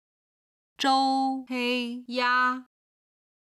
今日の振り返り！中国語発声